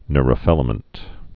(nrə-fĭlə-mənt, nyr-)